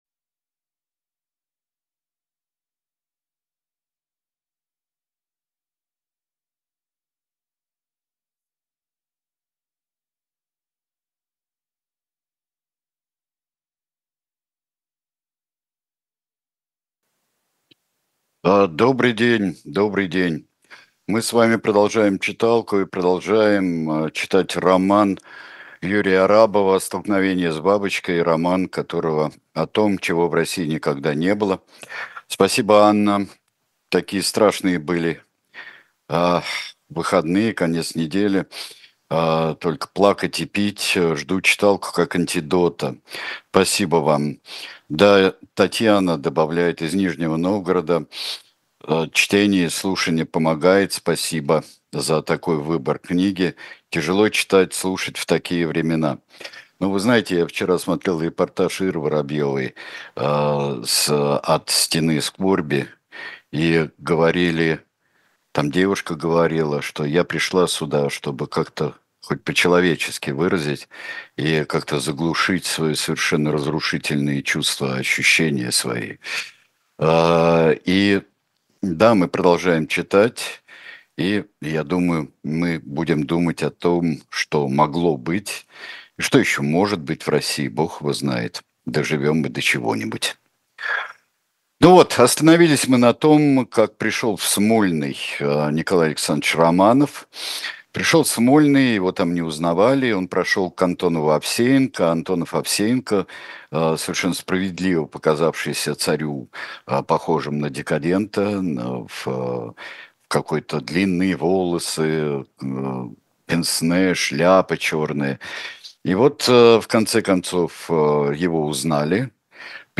Сергей Бунтман читает роман Юрия Арабова.